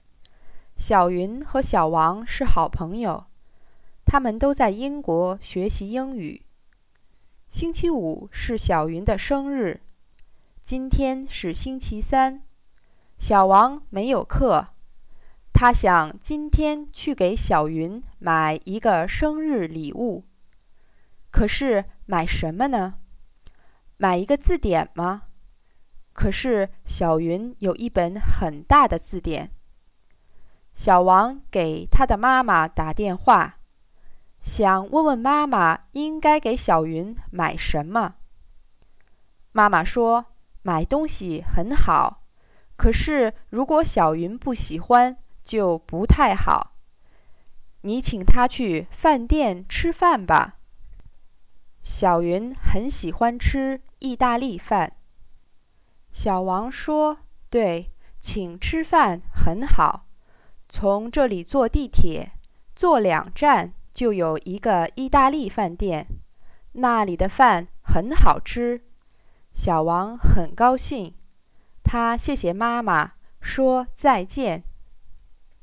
FAST
listeningfast.mp3